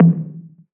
DDWV POP TOM 2.wav